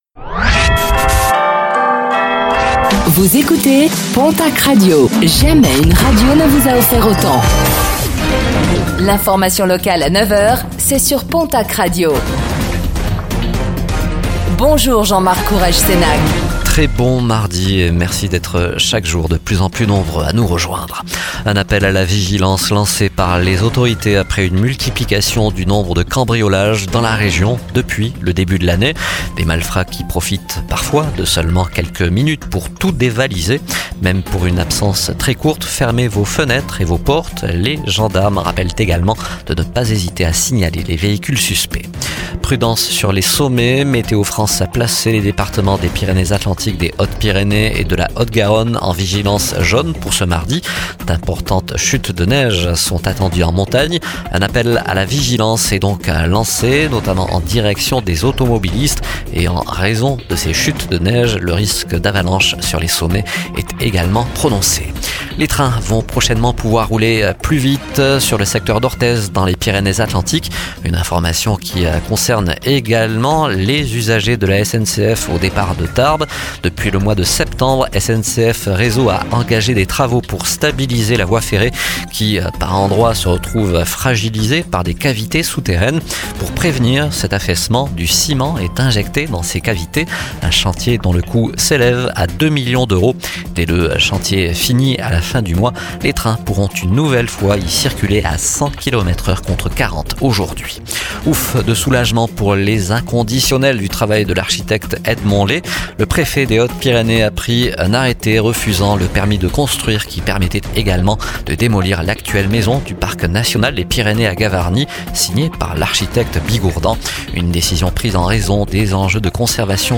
Réécoutez le flash d'information locale de ce mardi 25 février 2025